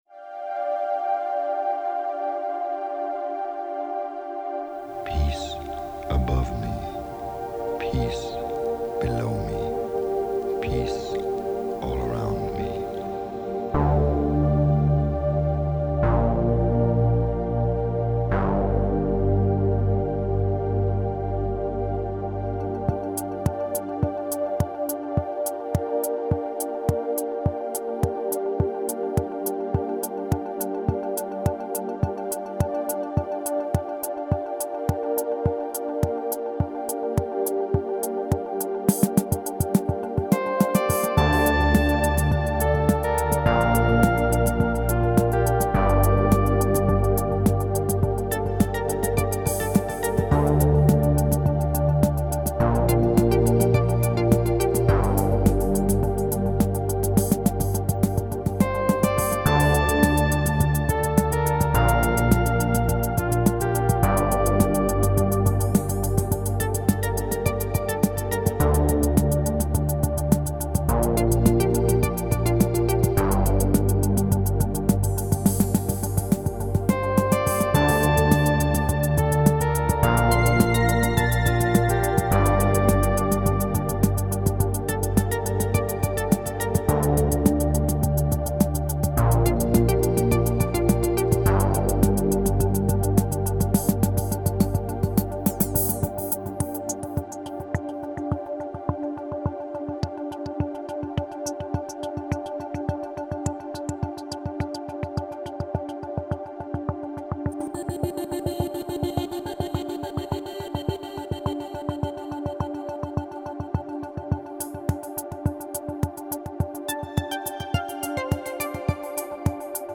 ambient track